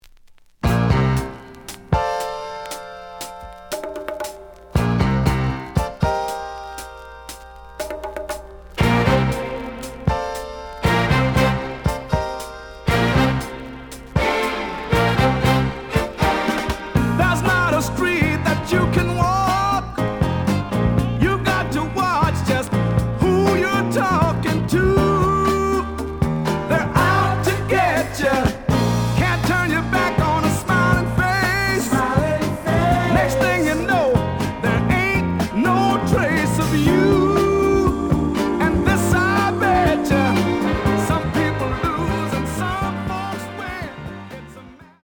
The audio sample is recorded from the actual item.
●Genre: Soul, 70's Soul
Edge warp. But doesn't affect playing. Plays good.)